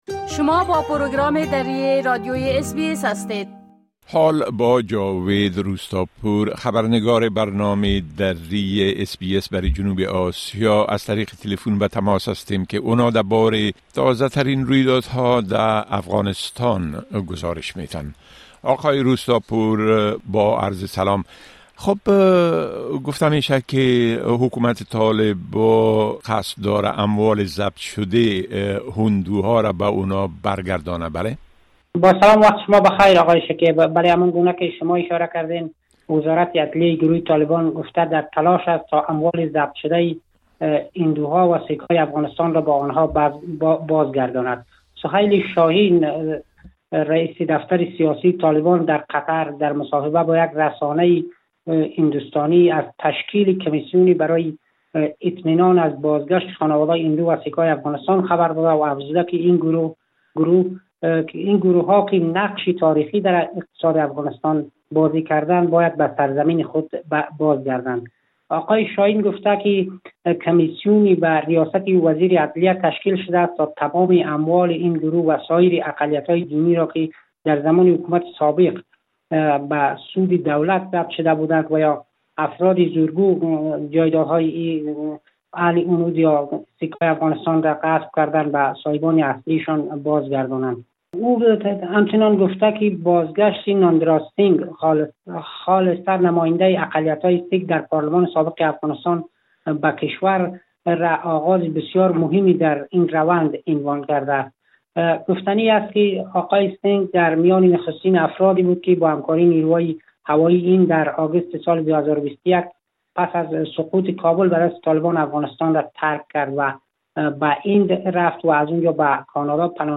گزارش كامل خبرنگار ما، به شمول اوضاع امنيتى و تحولات مهم ديگر در افغانستان را در اينجا شنيده مى توانيد.